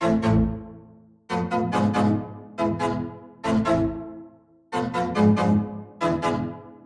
斯塔克弦乐
描述：大提琴上的刺痛声...
标签： 140 bpm Trap Loops Strings Loops 1.15 MB wav Key : A FL Studio
声道立体声